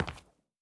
added stepping sounds
Linoleum_Mono_05.wav